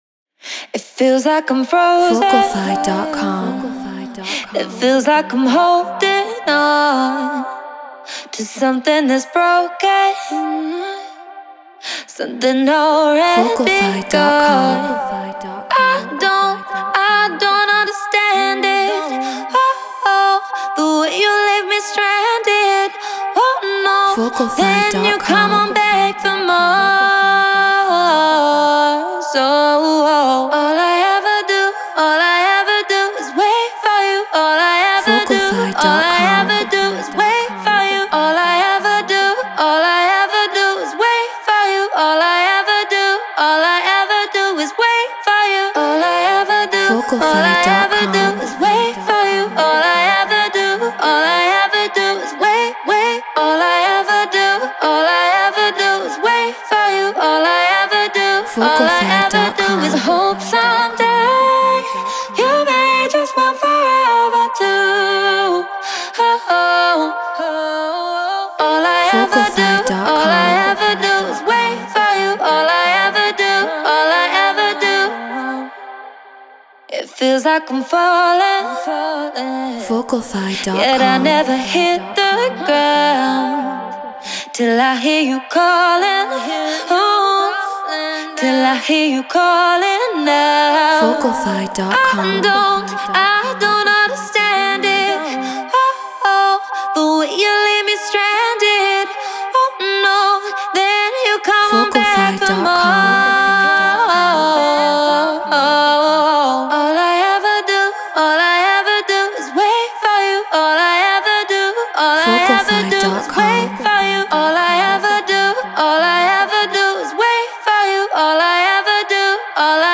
House 126 BPM Cmin